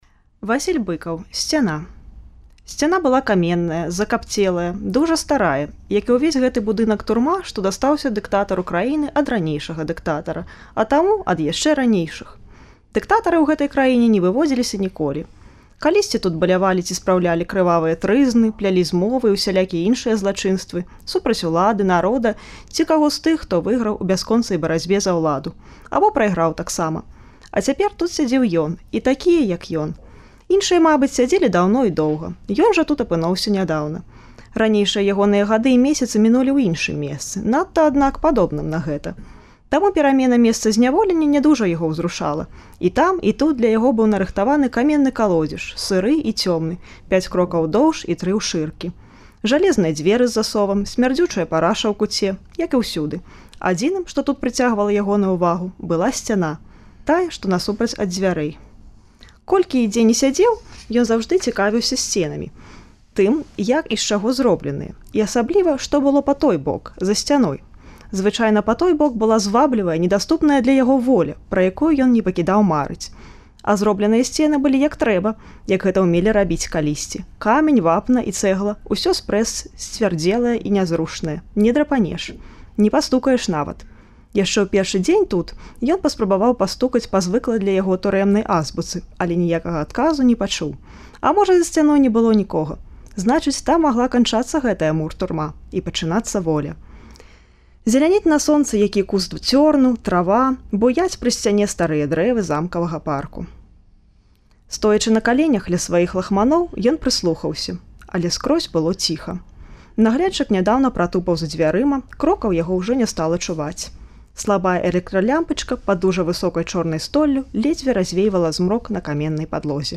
Штодня ў чэрвені выпускнікі Беларускага гуманітарнага ліцэю (цяперашнія і колішнія) чытаюць радкі з улюблёных быкаўскіх твораў.